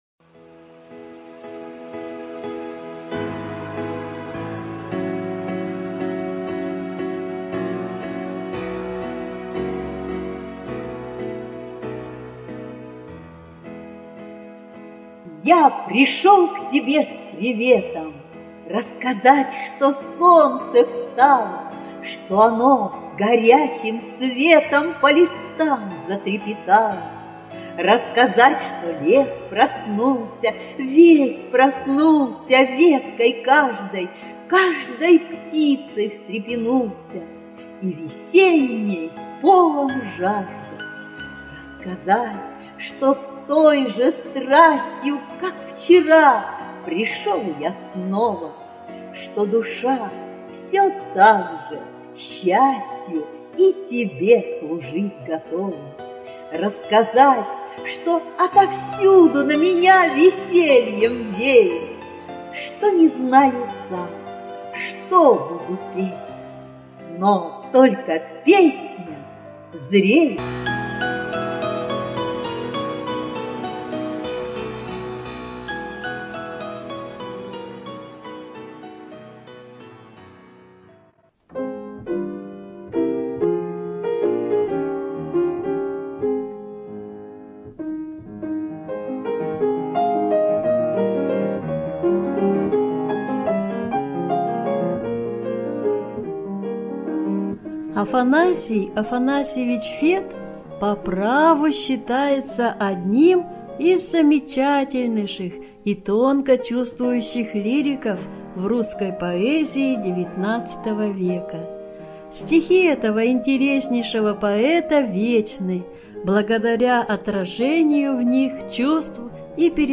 ЧитаетАвтор
ЖанрПоэзия